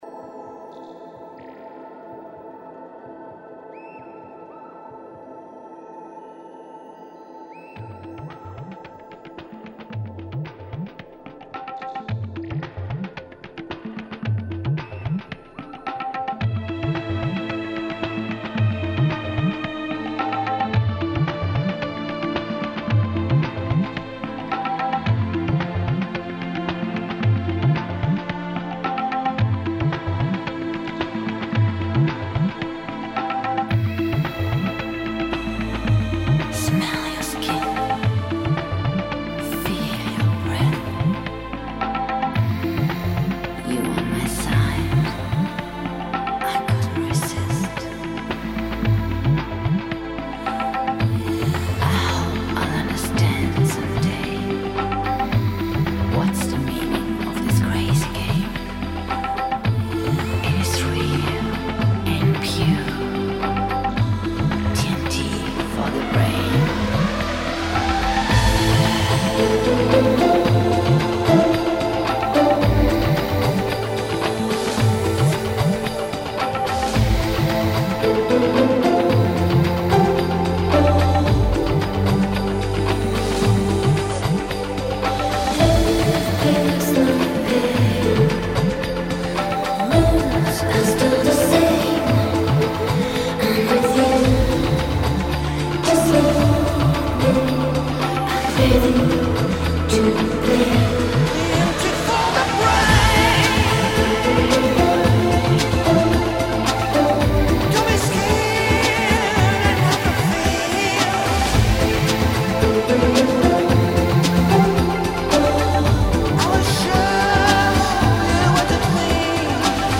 风格:发烧音乐 新音乐 迷幻